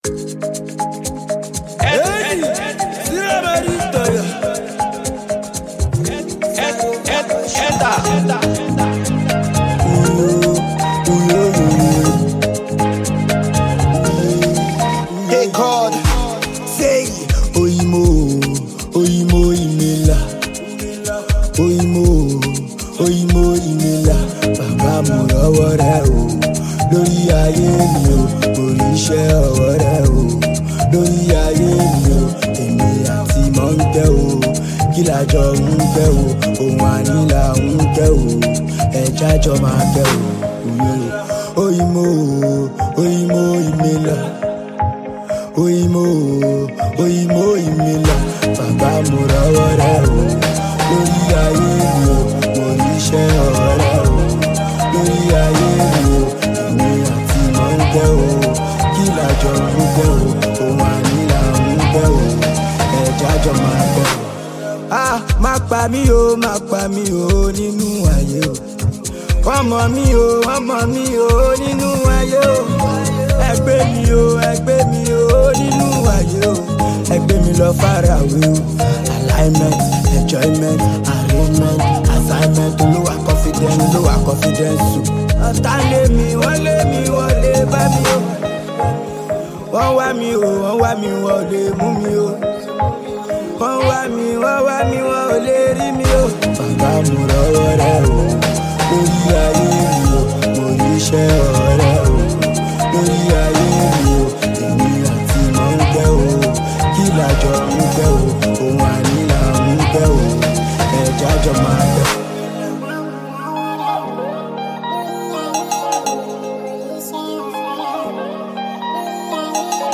delightful fusion of Afrobeat, reggae, and R&B